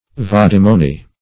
Search Result for " vadimony" : The Collaborative International Dictionary of English v.0.48: Vadimony \Vad"i*mo*ny\, n. [L. vadimonium.] (Law) A bond or pledge for appearance before a judge on a certain day.